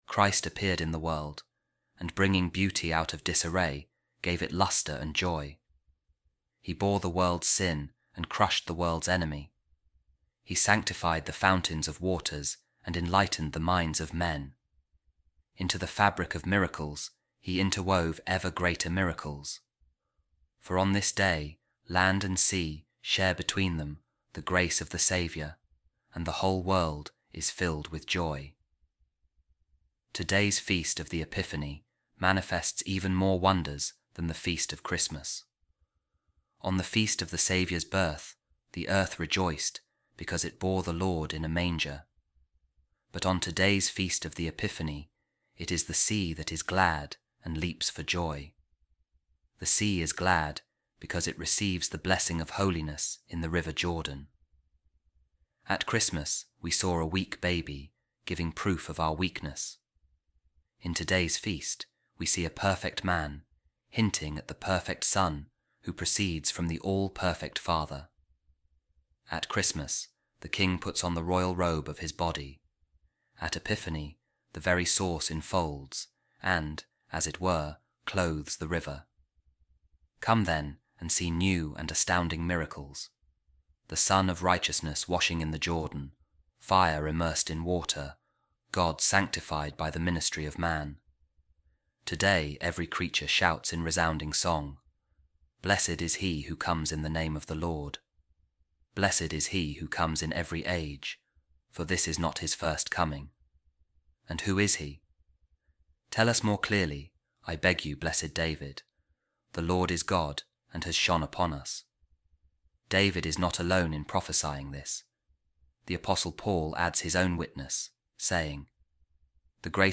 Office Of Readings | 9th January | A Reading From The Orations Of Saint Proclus Of Constantinople | The Sanctifying Of The Waters